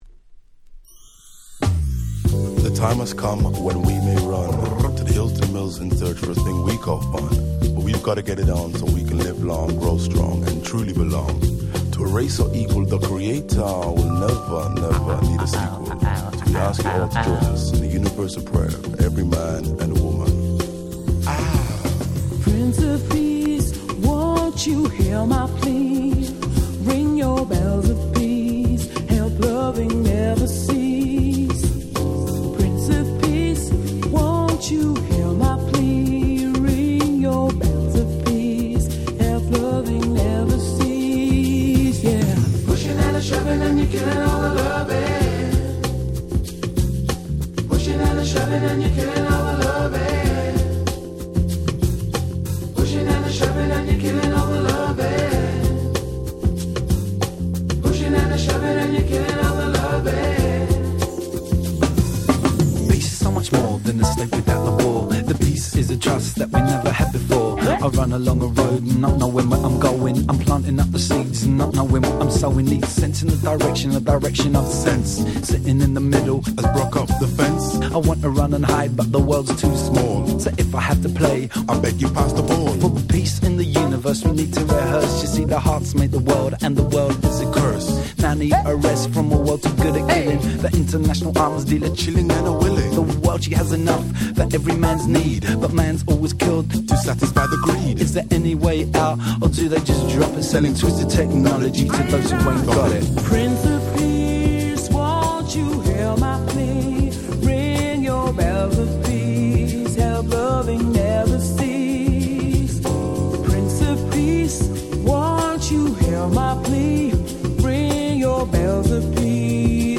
90's Acid Jazz Classics !!
オフィシャルリリースなので音質もバッチリ！！